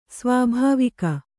♪ svābhāvika